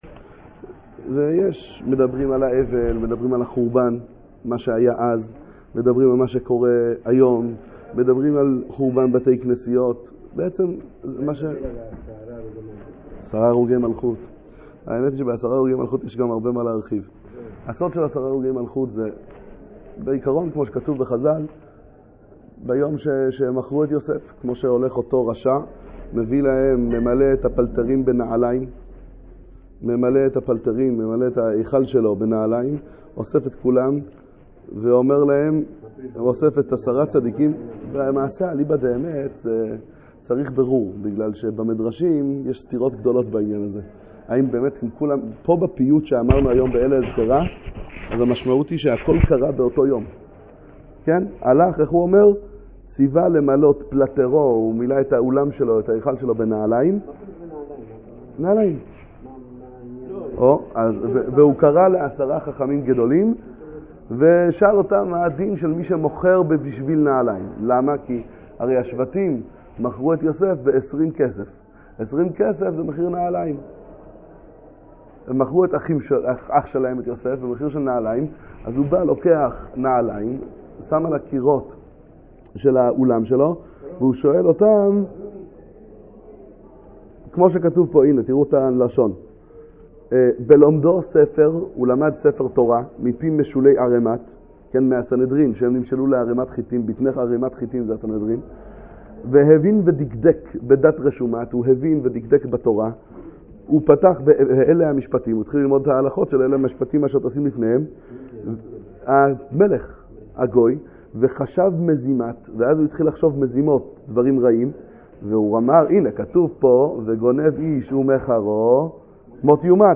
עשרה הרוגי מלכות - קהילת שובה ישראל בואנוס איירס ארגנטינה
שיעורי תורה לתשעה באב, ברי תורה לימי בין המצרים ותשעת הימים